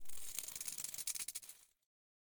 Minecraft Version Minecraft Version 1.21.5 Latest Release | Latest Snapshot 1.21.5 / assets / minecraft / sounds / block / creaking_heart / hurt / trail6.ogg Compare With Compare With Latest Release | Latest Snapshot